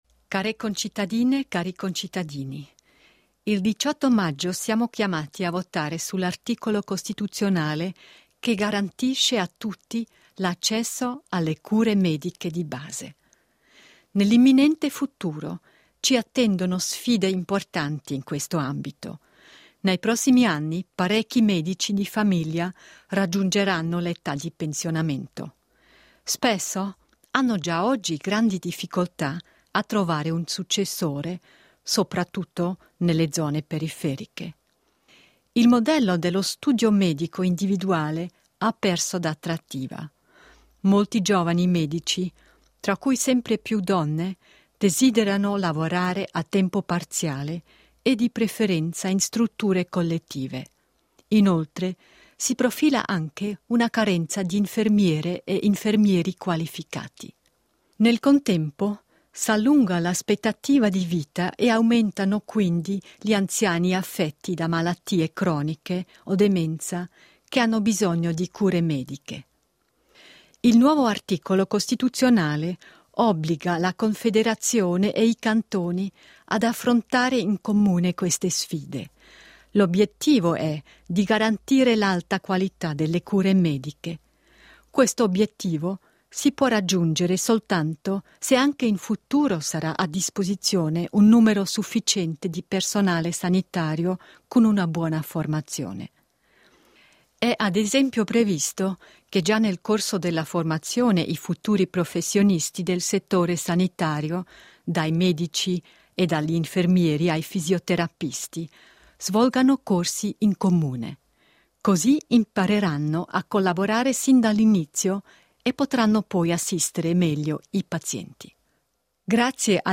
Votazione federale del 18.05.2014 (MP3, 2 MB, 08.05.2014)Dichiarazione del Consiglio federale
Consigliera federale Simonetta Sommaruga
Conferenza stampa del Consiglio federale del 24.2.2014